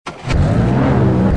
Home gmod sound vehicles enzo
start.mp3